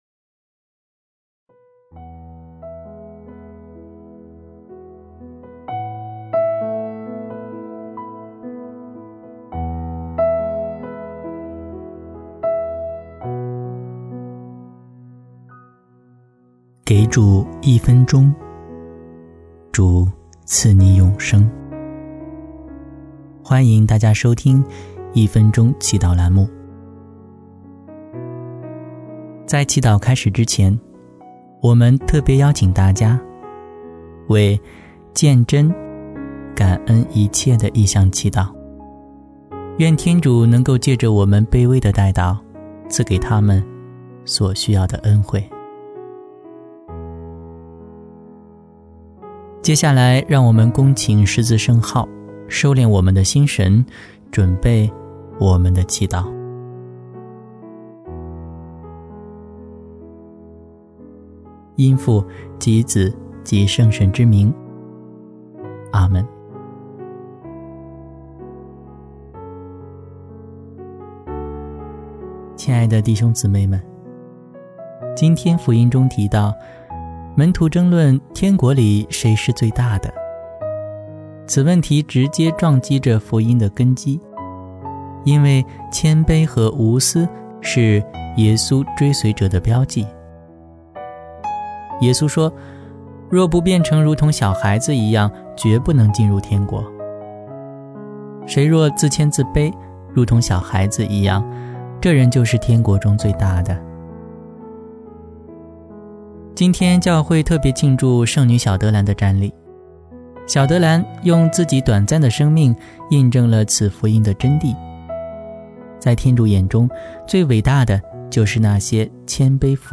10月1日祷词